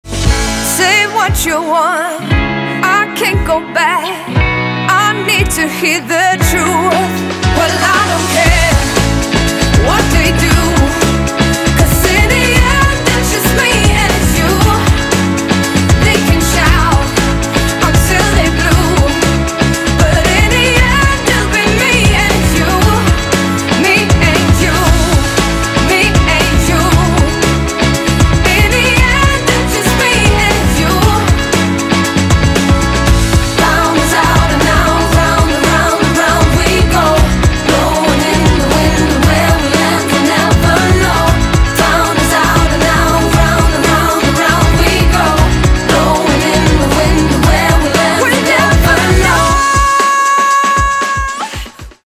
• Качество: 192, Stereo
вокал
швейцарская поп-певица.